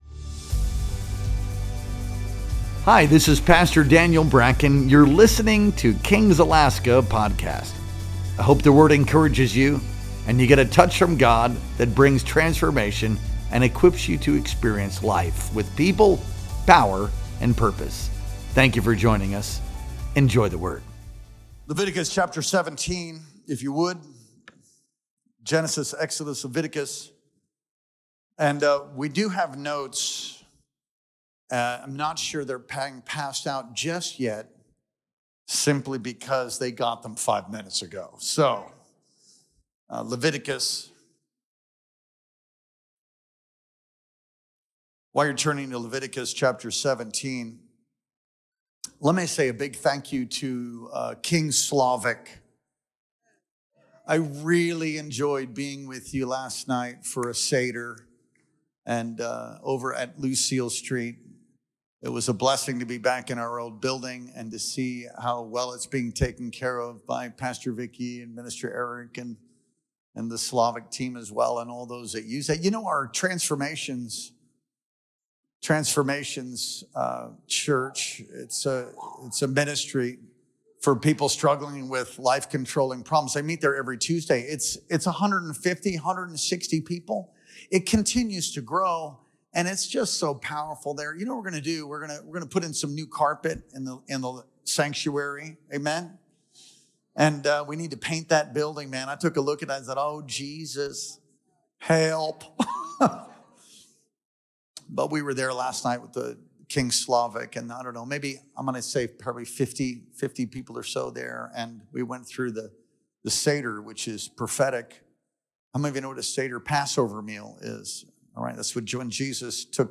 Our Good Friday Worship Experience streamed live on the evening of April 18th, 2025.